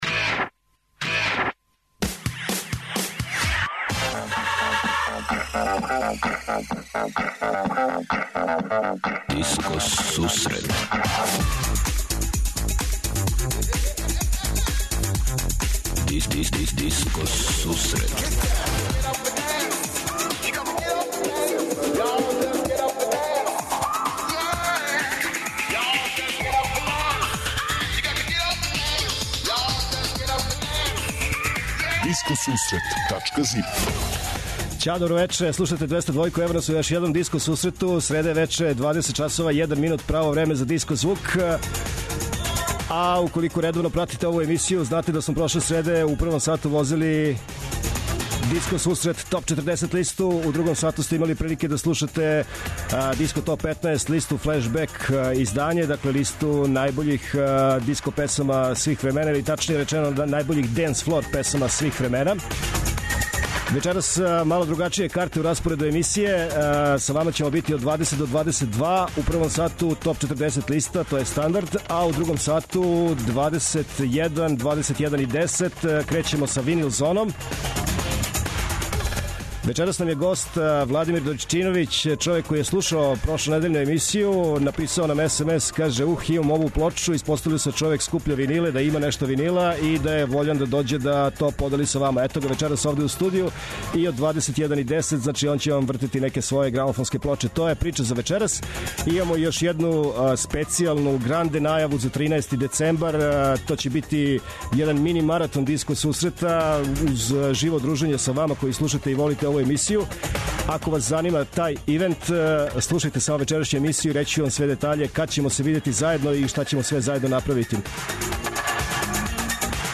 Oд 21:10ч Винил ЗонаСлушаоци, пријатељи и уредници Диско Сусрета за вас пуштају музику са грамофонских плоча.